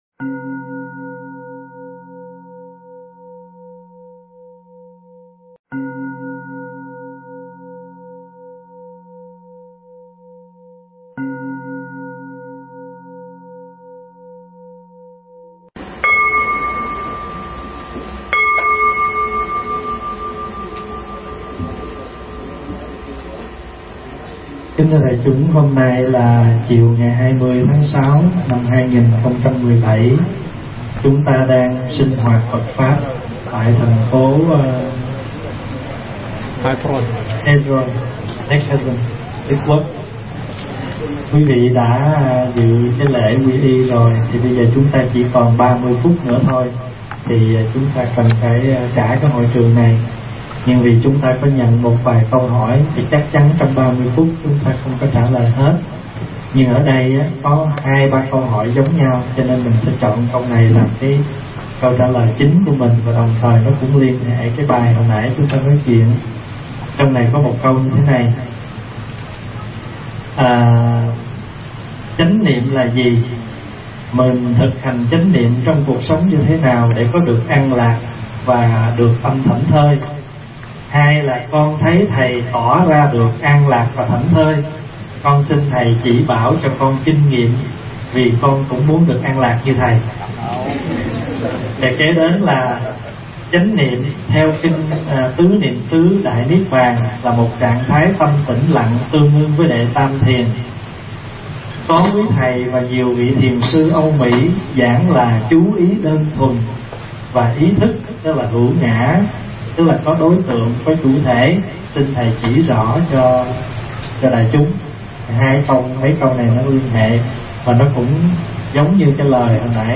Nghe Mp3 thuyết pháp Chăm Sóc Đất Tâm Phần 2 – Đại Đức Thích Pháp Hòa
Tải mp3 Thuyết Pháp Chăm Sóc Đất Tâm Phần 2 – Đại Đức Thích Pháp Hòa thuyết giảng tại Heilbronn, ngày 20 tháng 6 năm 2017